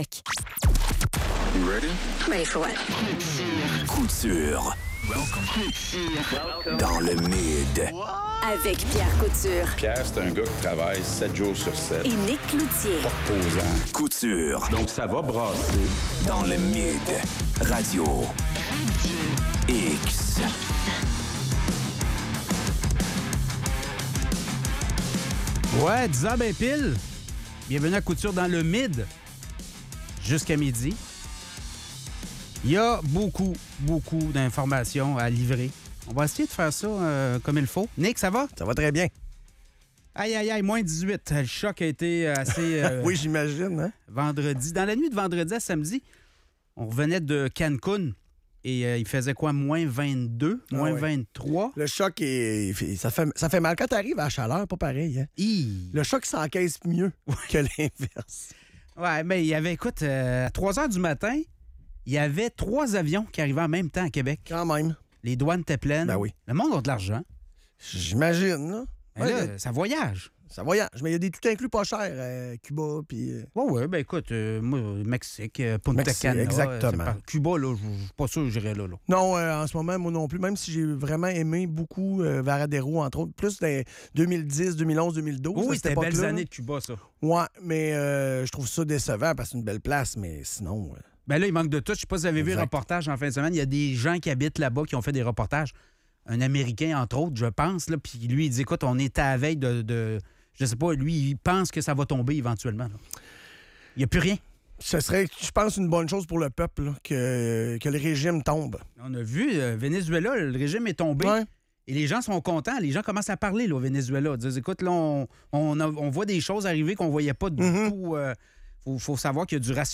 La Ville de Québec fait face à un manque crucial de fonds pour maintenir et améliorer ses infrastructures. Les animateurs soulignent les défis financiers auxquels la municipalité est confrontée et l'impact que cela peut avoir sur les services offerts aux citoyens.